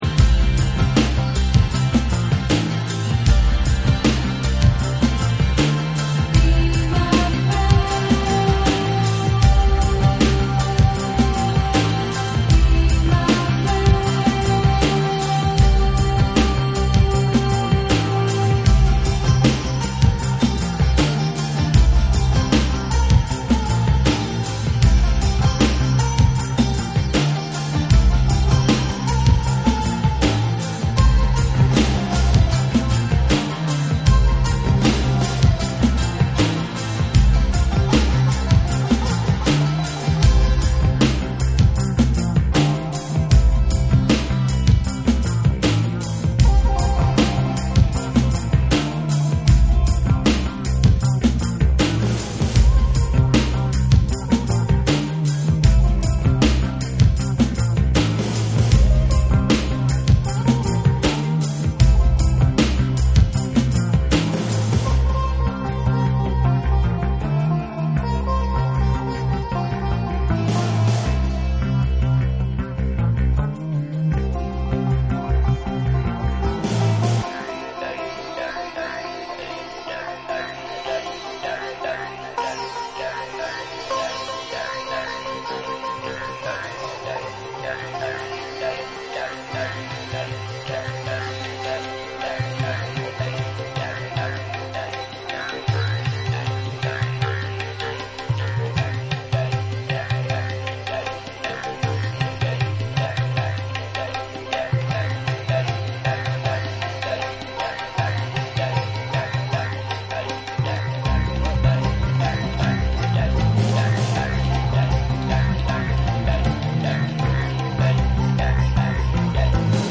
UKエイジアンの女性シンガー
オリジナルに忠実なイントロからダウンテンポに展開する絶品オリエンタル・グルーヴ "REMIX" 。
NEW WAVE & ROCK / ORGANIC GROOVE